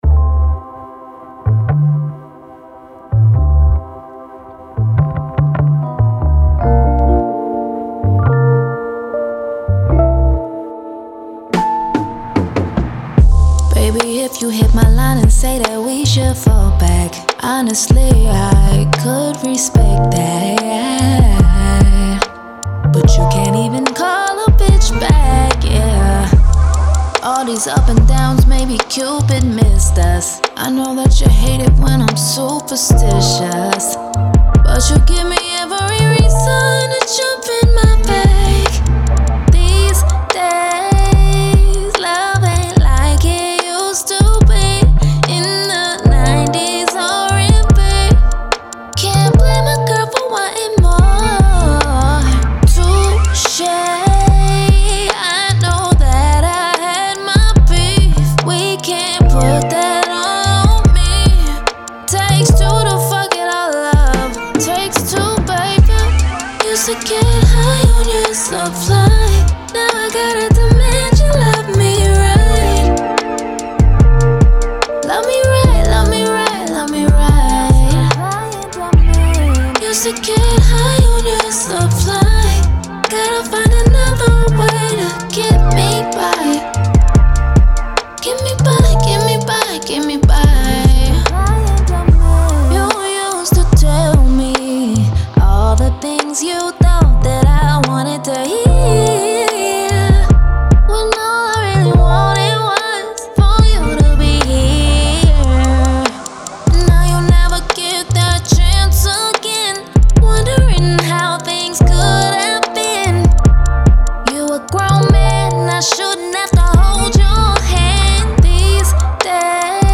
R&B, Soul
D Min